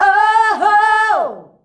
OOOHOO  A.wav